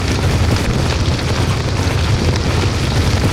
fire_large_flames_magic_loop_01.wav